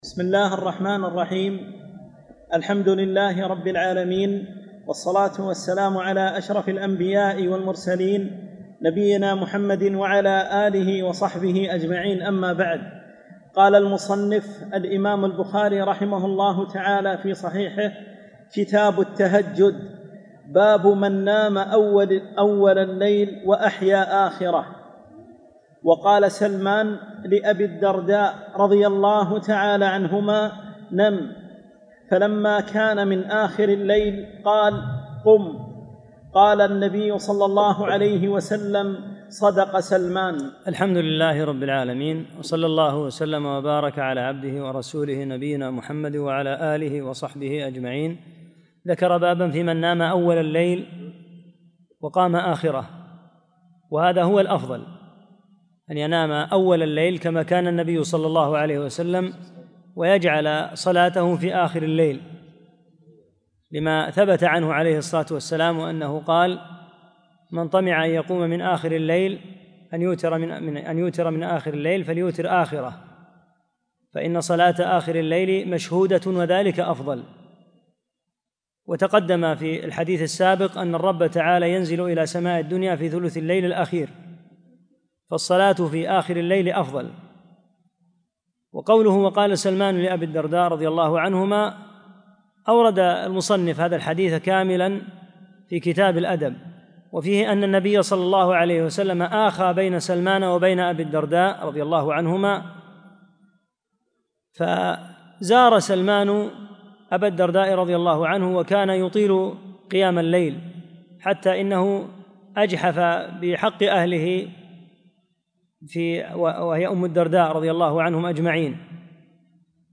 3- الدرس الثالث